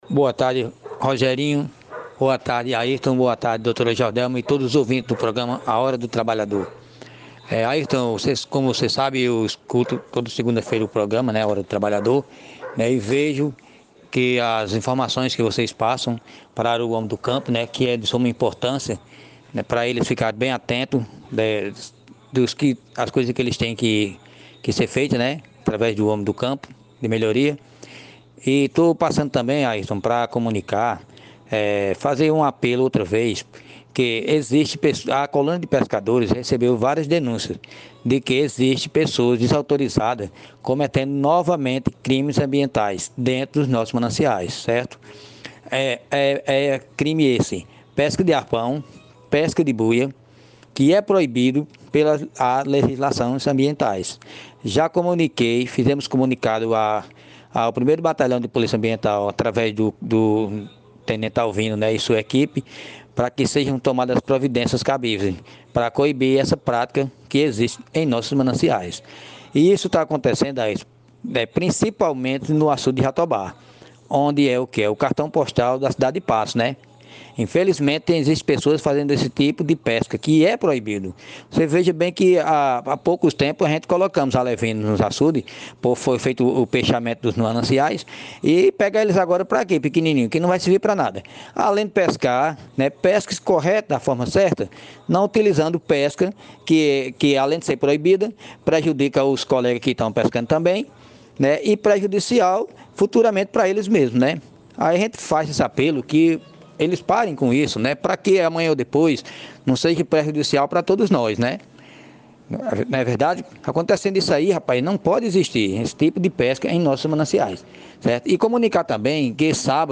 A revelação foi feita na tarde desta segunda-feira (26), durante o programa radiofônico A Hora do Trabalhador, de responsabilidade da Fetag-PB, na Rádio Espinharas FM 105,1.